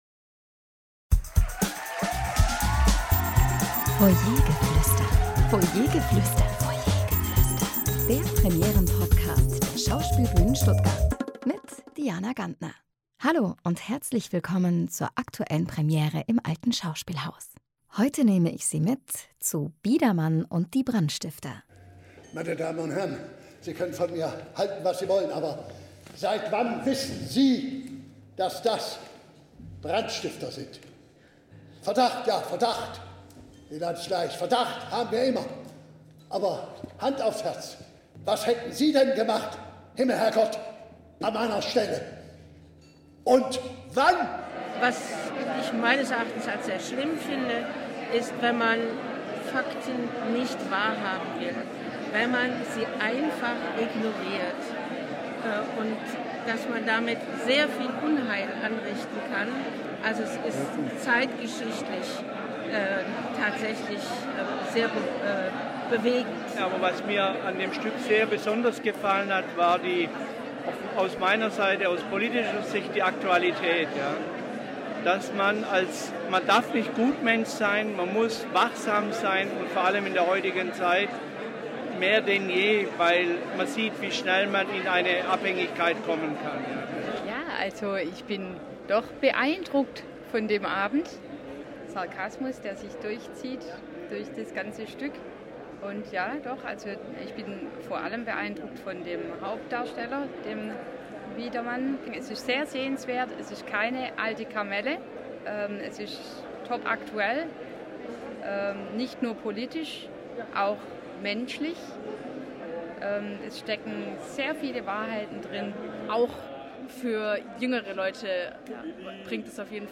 Zuschauerstimmen zur Premiere von “Biedermann und die Brandstifter”